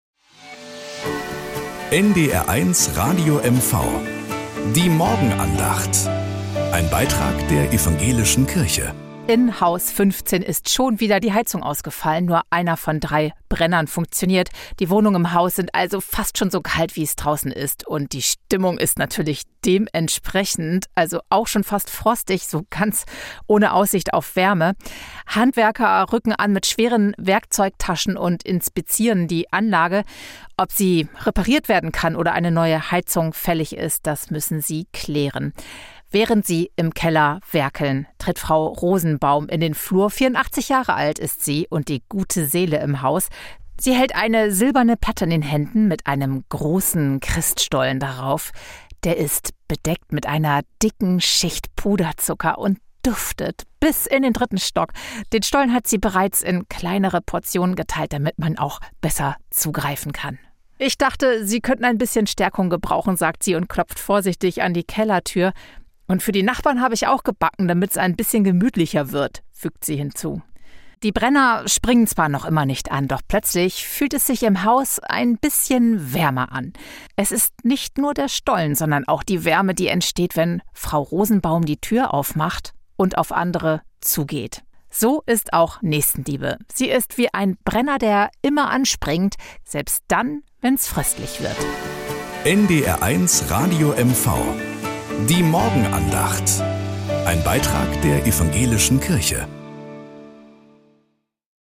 1 Morgenandacht bei NDR 1 Radio MV 1:48
Evangelische und katholische Kirche wechseln sich dabei ab. Am Montag auf Plattdeutsch.